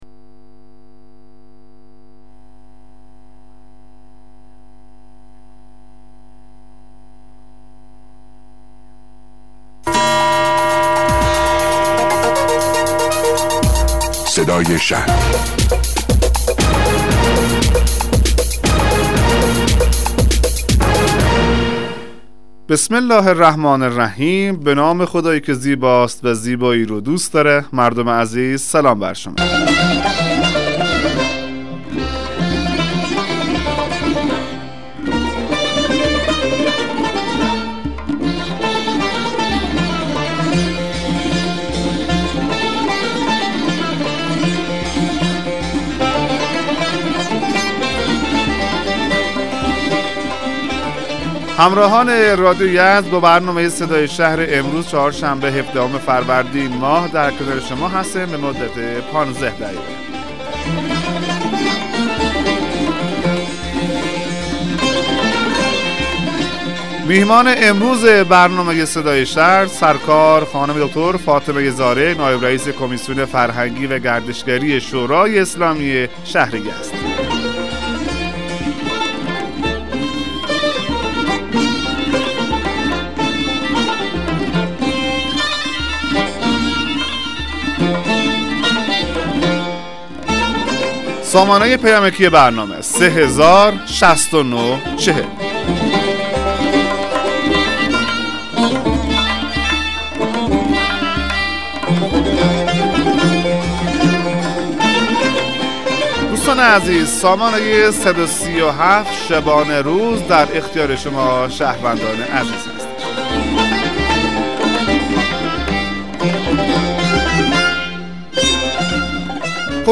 مصاحبه رادیویی برنامه صدای شهر با حضور فاطمه زارع عضو شورای اسلامی شهر یزد